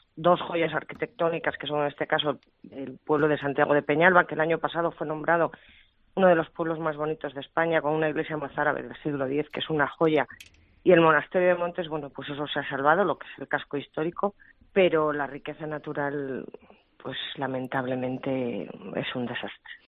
Gloria Fernández Merayo, alcaldesa de Ponferrada